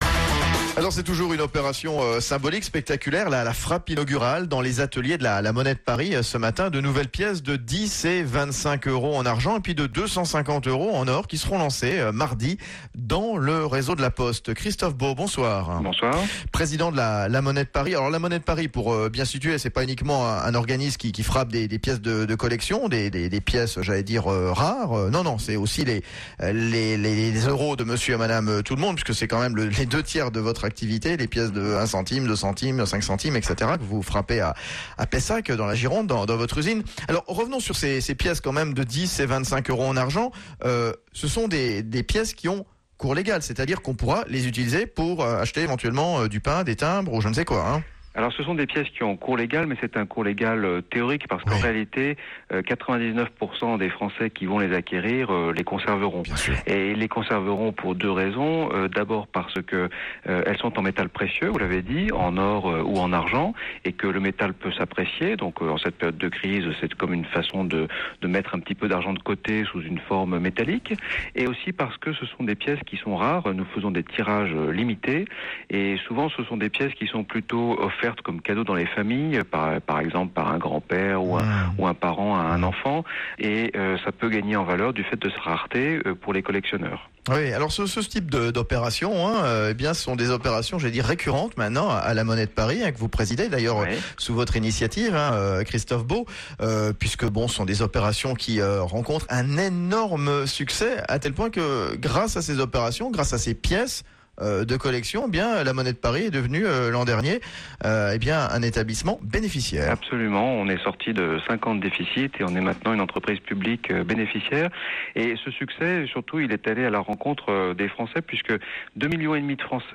Interviews
extrait de la radio BFM